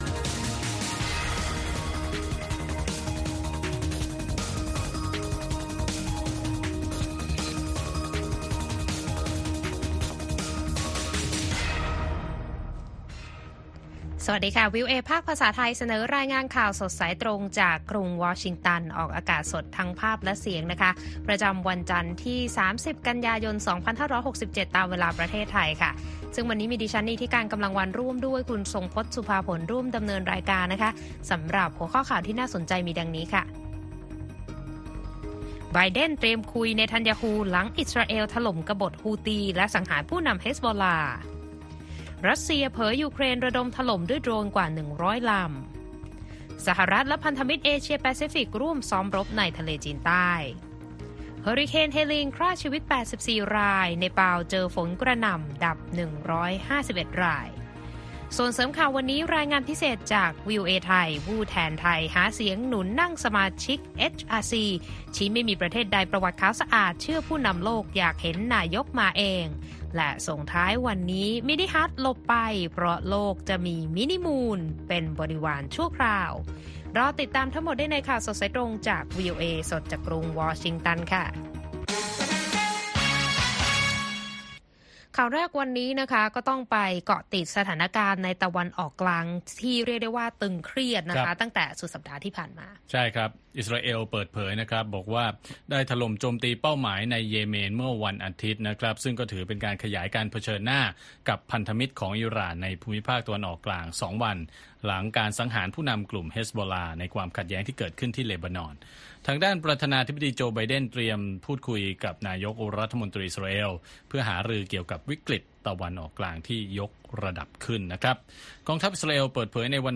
ข่าวสดสายตรงจากวีโอเอไทย จันทร์ ที่ 30 ก.ย. 67